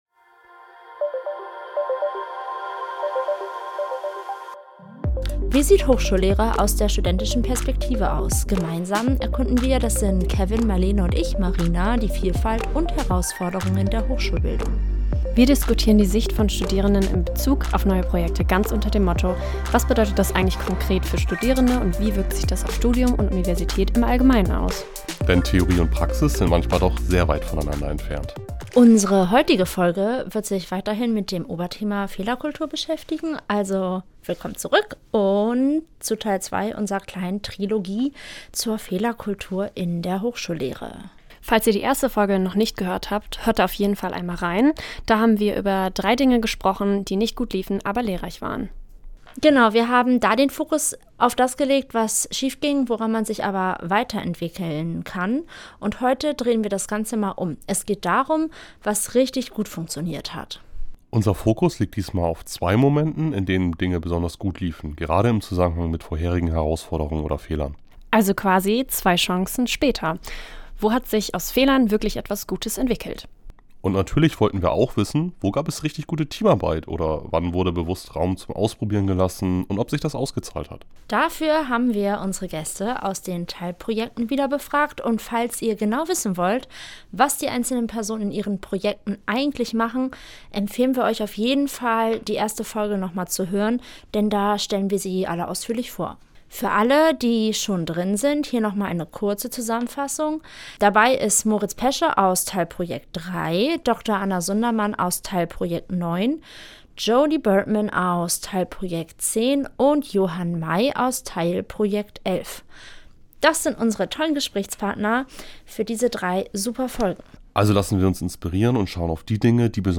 In dieser Folge reden wir über genau solche Situationen: Entscheidungen, die sich ausgezahlt haben, Teamarbeit, die funktioniert hat, und Ideen, die tatsächlich gefruchtet haben, trotz aller Widrigkeiten. Unsere vier Gäste aus dem Projekt DigiTaL berichten ehrlich, was sie ermutigt, überrascht und weitergebracht hat und warum manchmal gerade das Ungeplante am besten klappt.